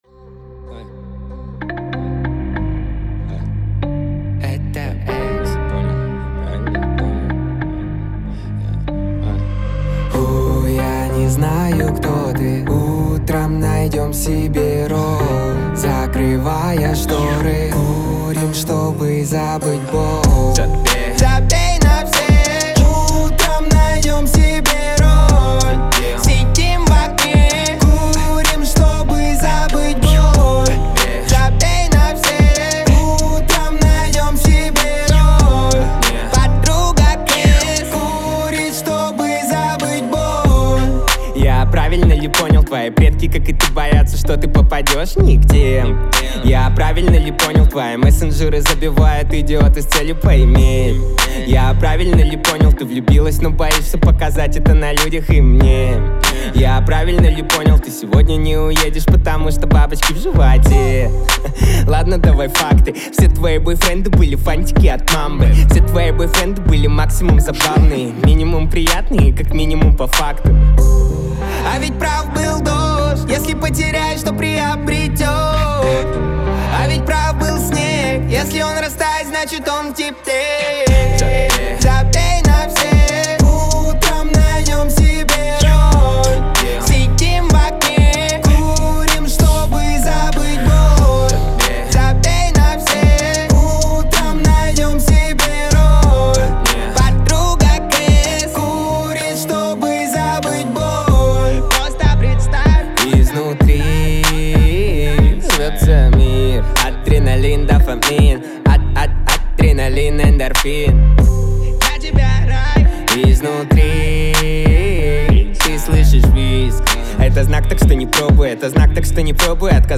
яркими мелодиями и эмоциональным вокалом